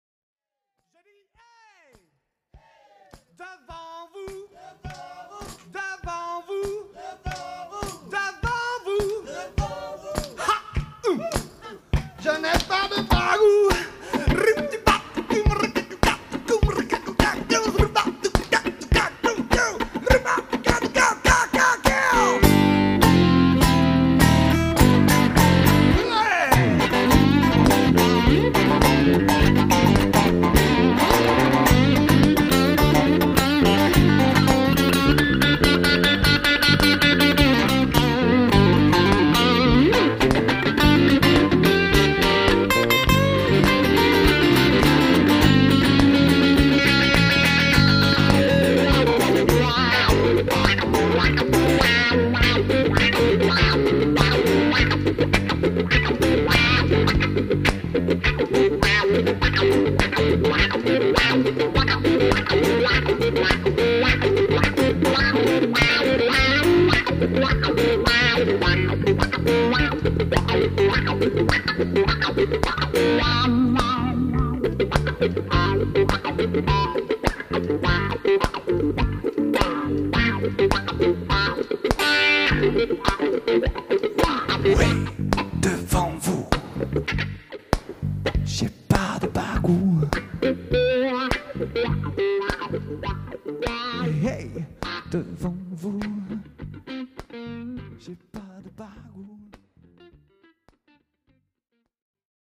Enregistré en concert vivant, le 10/02/2001.
dans un style musical connoté blues.